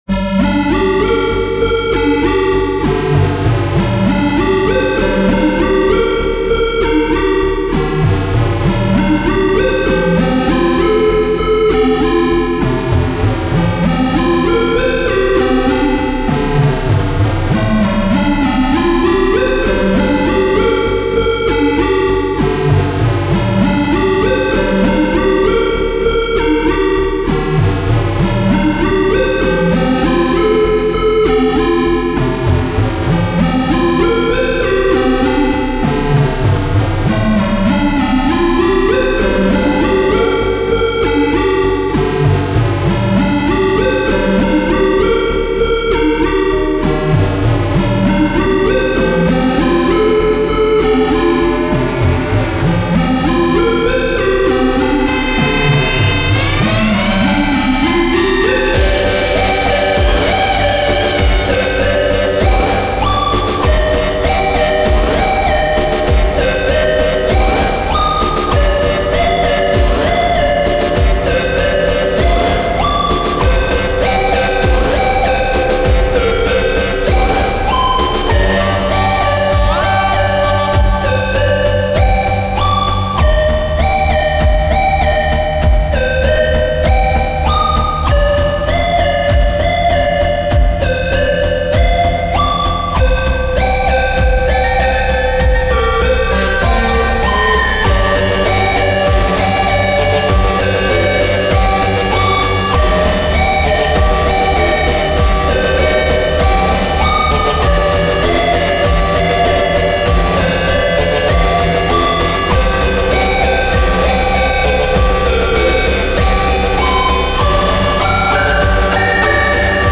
Can’t explain cz I love how crunchy the ost is :3 very nice!